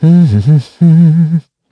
Clause_ice-Vox_Hum_kr.wav